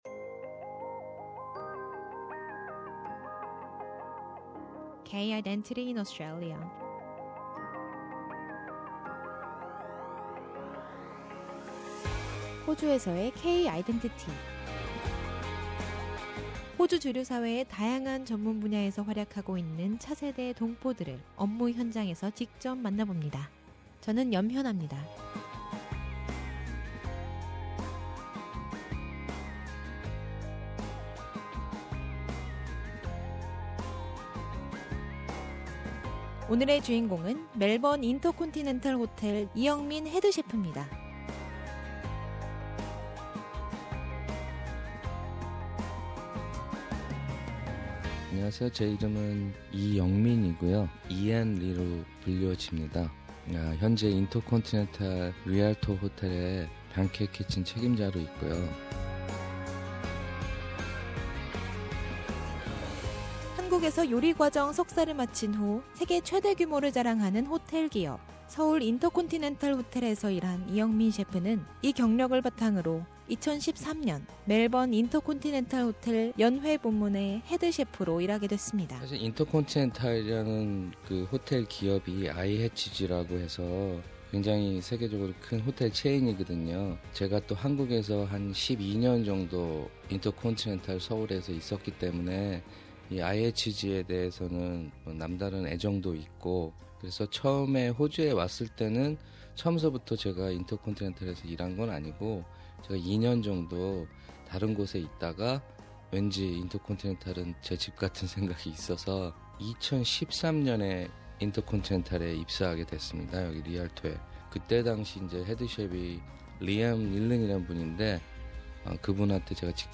SBS Radio studio